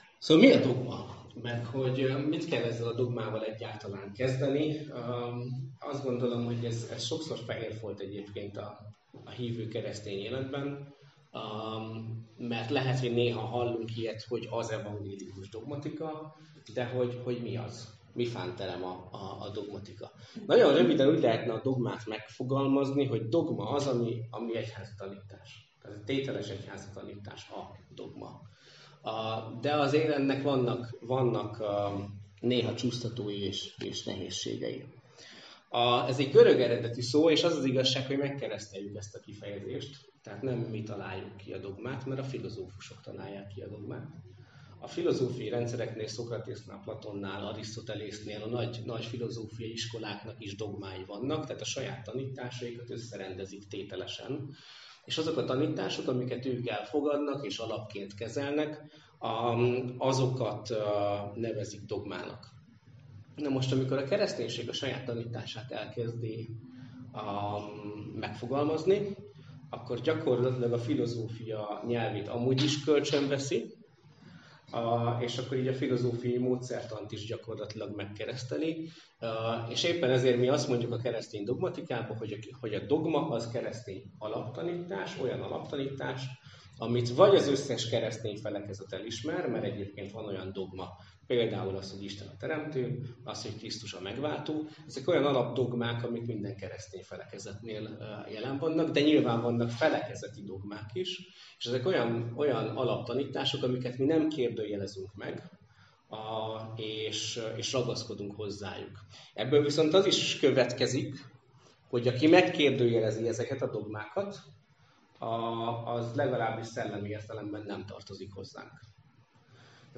Mi a dogma - Felnőtt hittan Hegyeshalomban